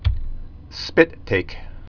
(spĭttāk)